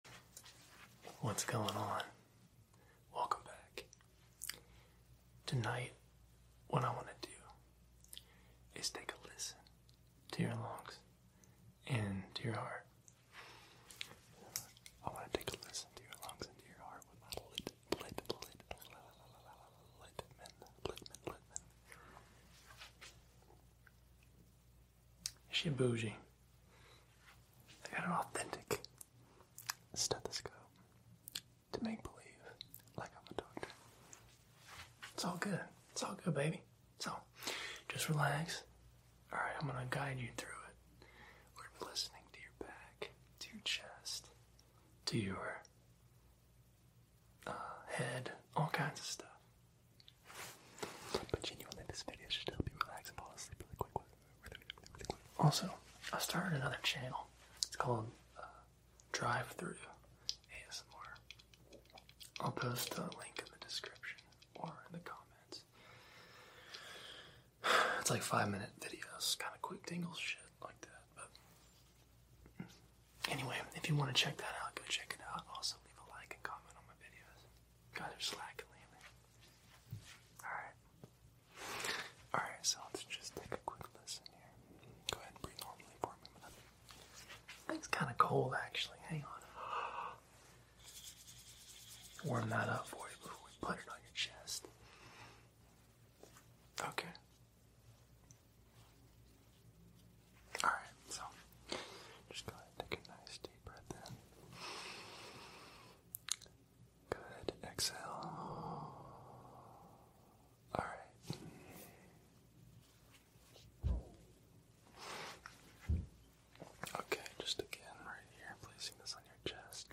ASMR Listening to you breathe